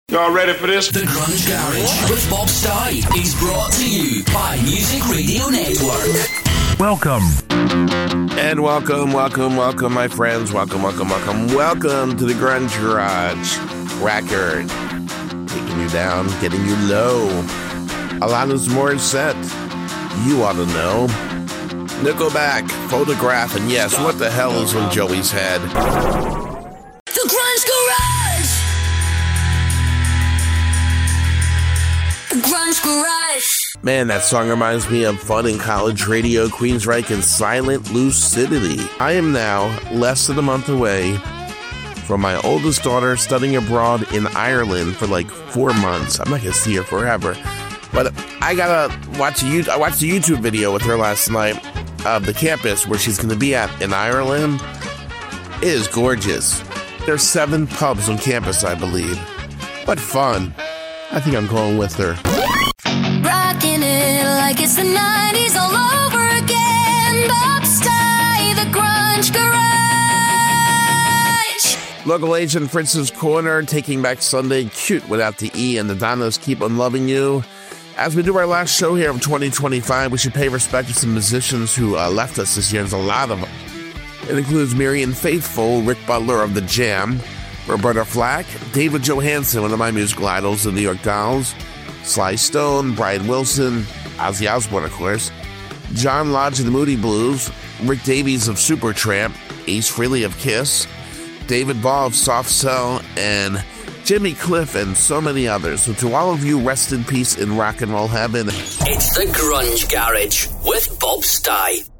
grungegaragedemo2026.mp3